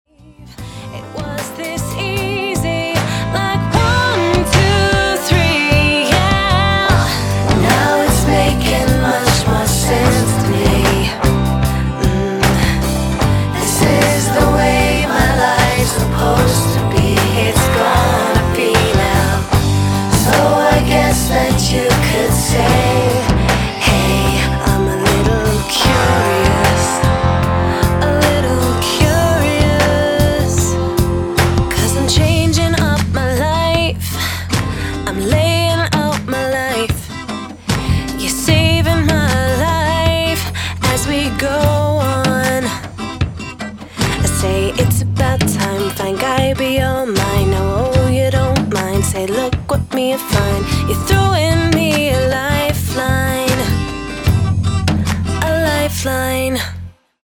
pop artist
ballads
with their powerful emotion.